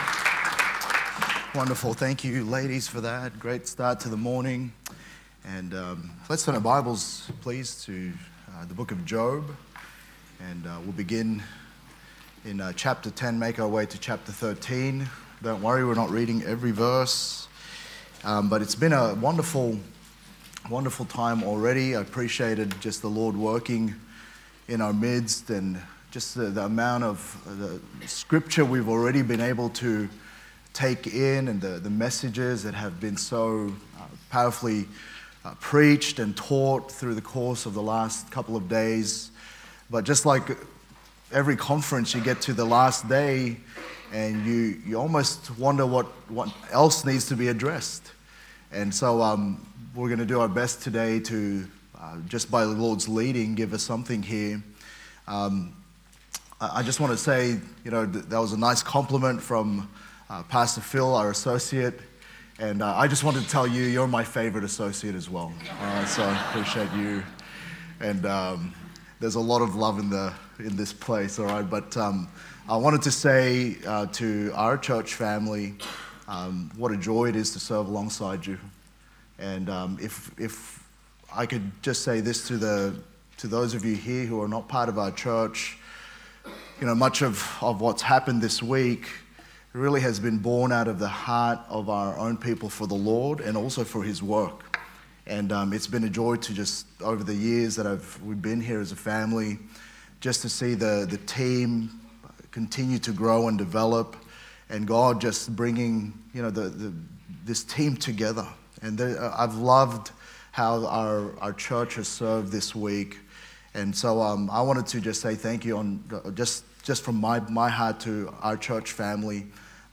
Sermons | Good Shepherd Baptist Church
Wed 1st Session Selah Conference 2026